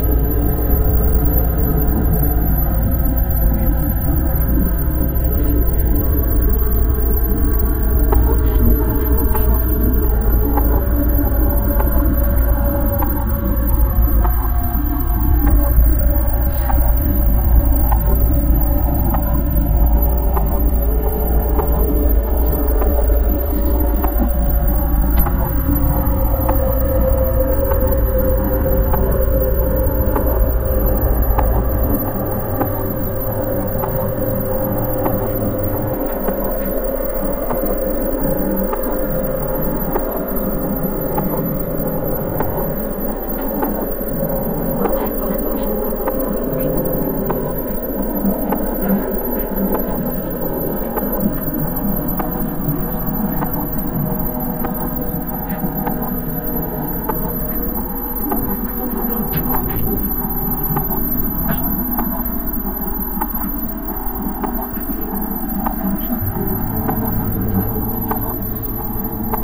cooll train 3.wav